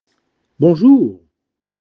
• Pronunciation Tip: The “n” in bon is nasalized – don’t pronounce it like in English. Try: /bɔ̃.ʒuʁ/